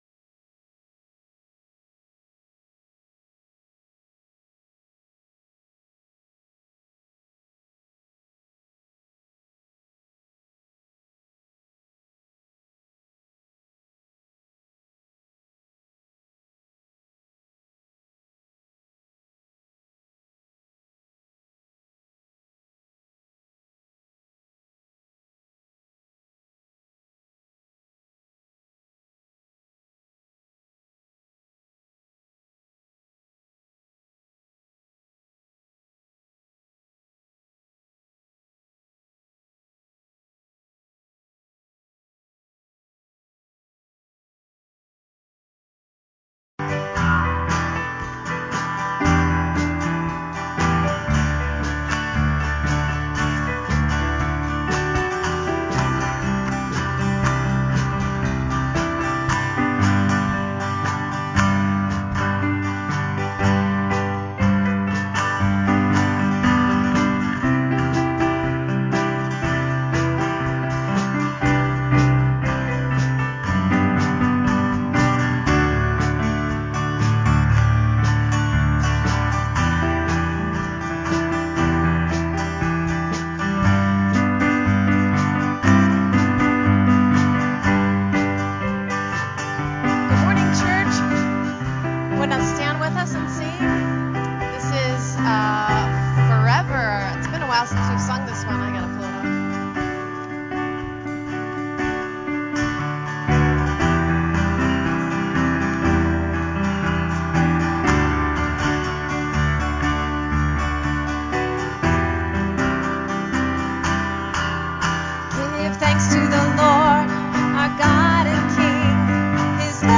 Praise Worship
Announcements
Prayer requests and Praises